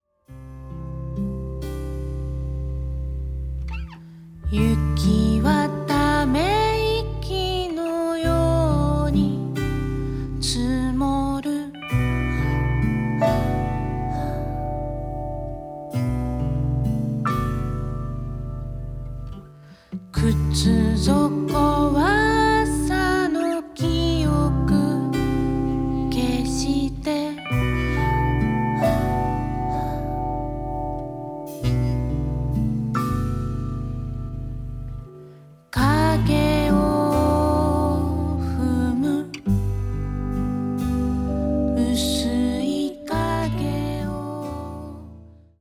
震える子猫を抱くような歌声。
スティール・ギターやサックス、リコーダーにヴィブラフォンも加わった７人体制のバンドサウンドはほんのりポップな装い。